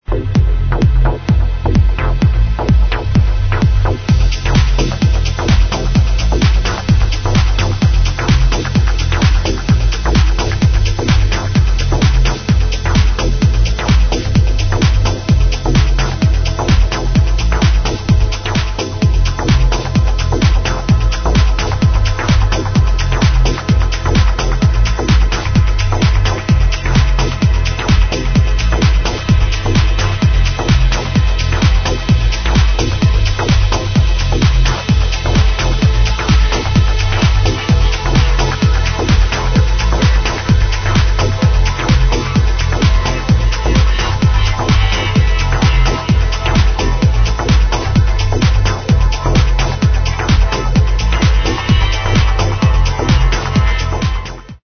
something like DUB of the wellknown track
great basslines!
cuted from the nice prpogressive House mix